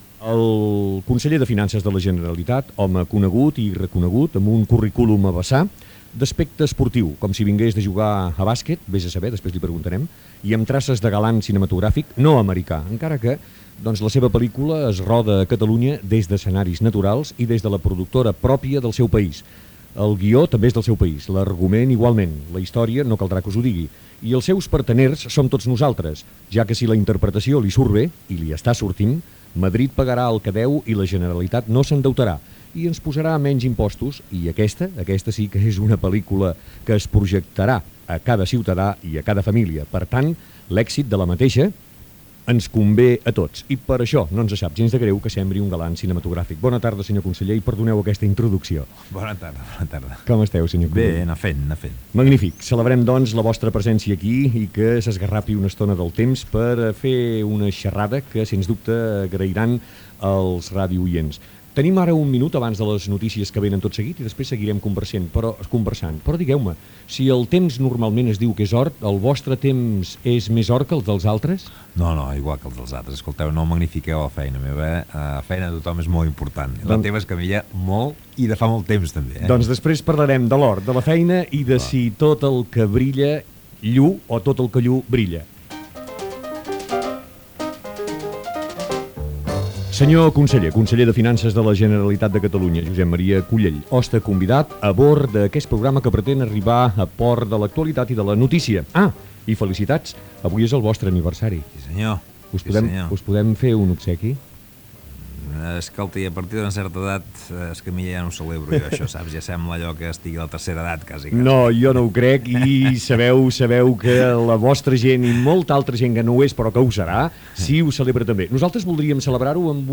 Presentació i introducció al convidat del programa:Josep Maria Cullell, Conseller de Finances de la Generalitat de Catalunya. Música i anècdota de l'aniversari del polític. Conversa sobre política.
Entreteniment